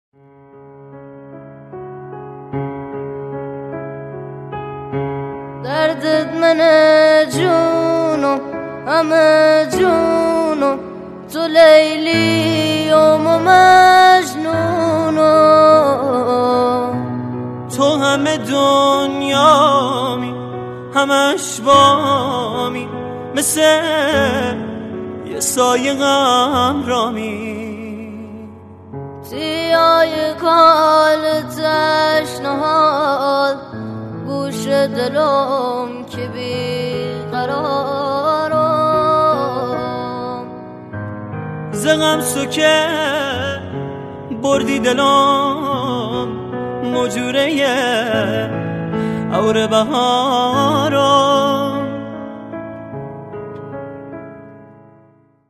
آهنگ لری
بسیار صدای دلنشینی دارند.